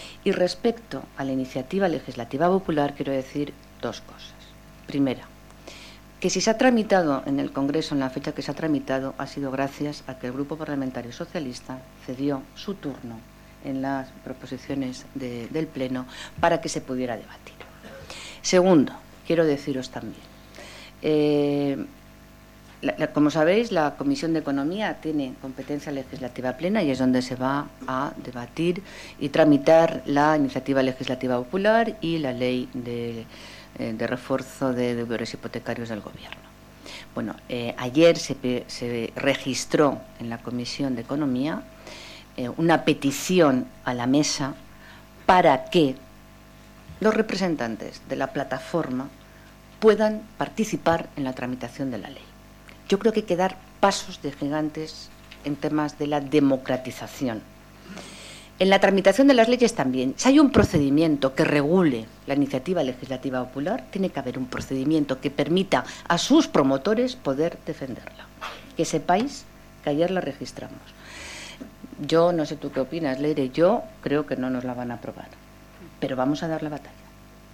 Inmaculada Rodríguez Pinero inaugura la jornada sobre reformas hipotecarias organizada por el Grupo Parlamentario Socialista 13/03/2013